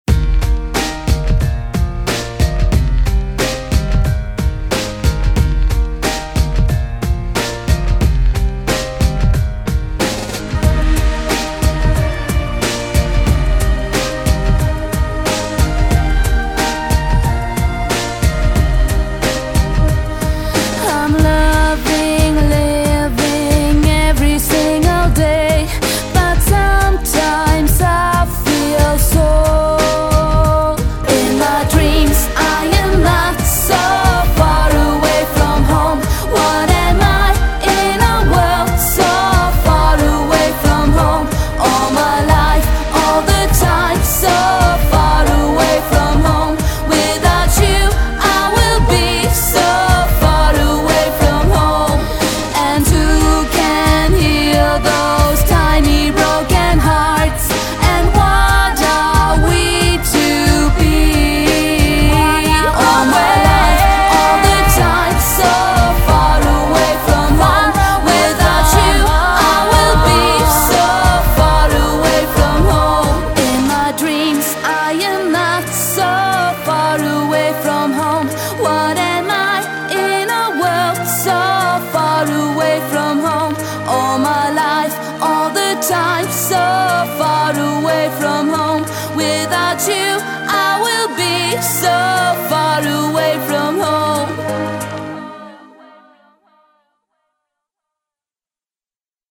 BPM91--1
Audio QualityPerfect (High Quality)